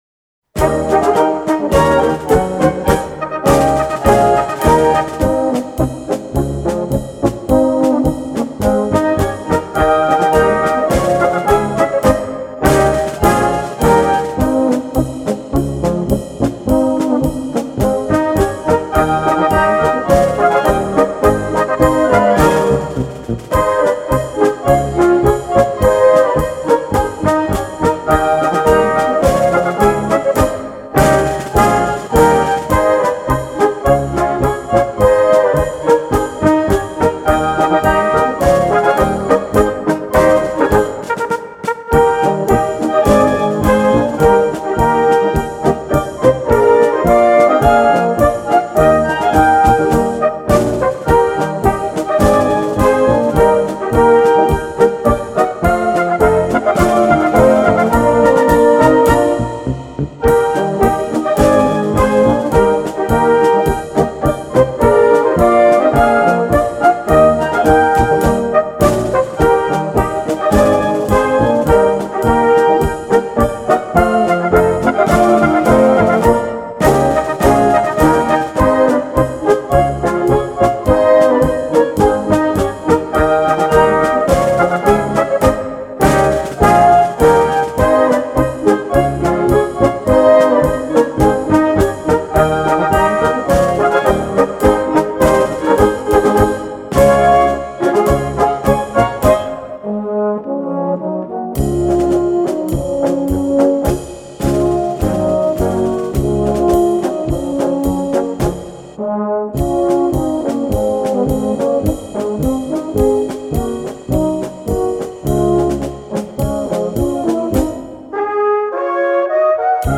Polka für Blasorchester Schwierigkeit
3:15 Minuten Besetzung: Blasorchester PDF